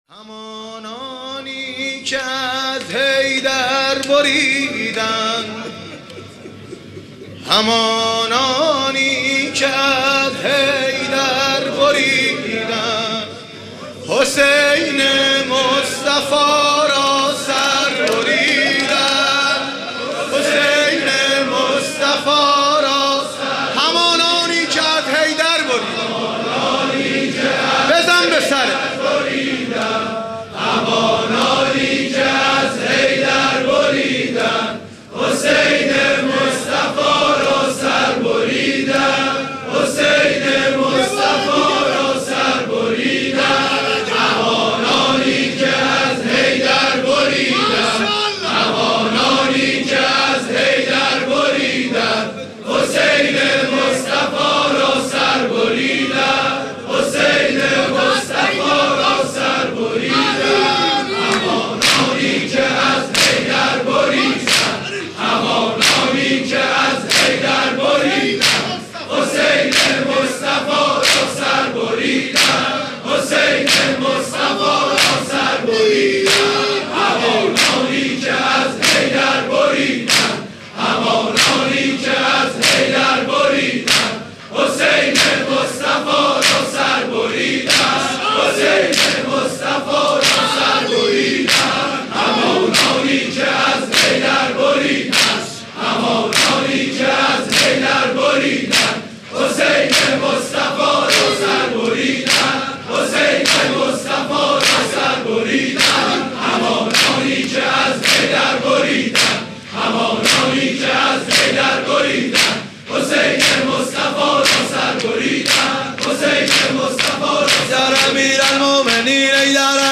مناسبت : شب بیست و سوم رمضان - شب قدر سوم
قالب : دو دمه